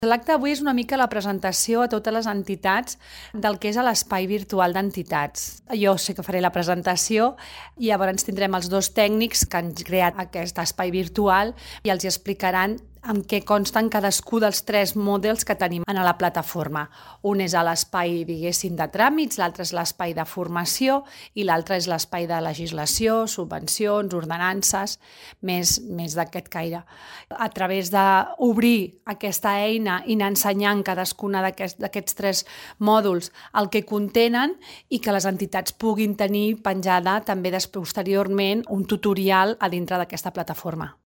Àngels Soria, regidora de Teixit Associatiu